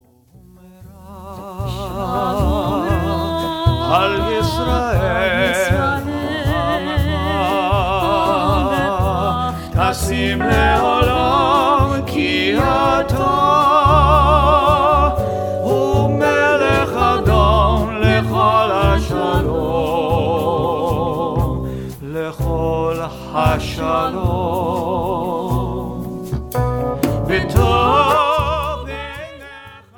2-Part Choir or 2 Voices/Piano
• Choral
CJM (Contemporary Jewish Music)